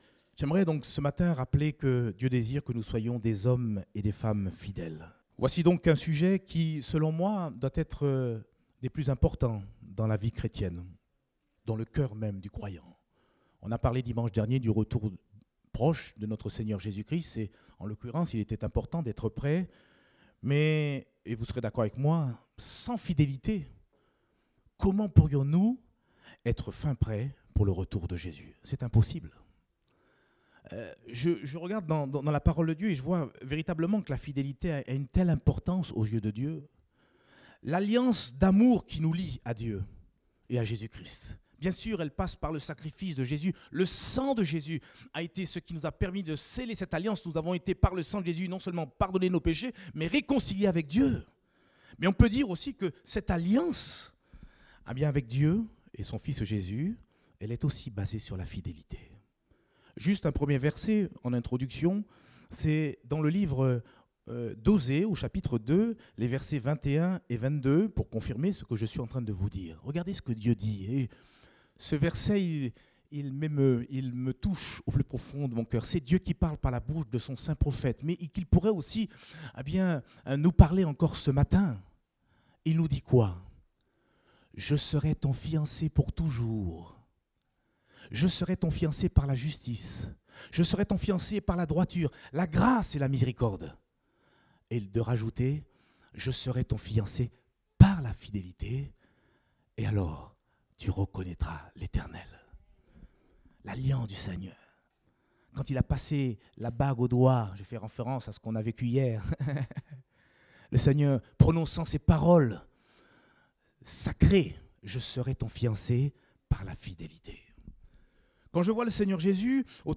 Date : 3 septembre 2017 (Culte Dominical)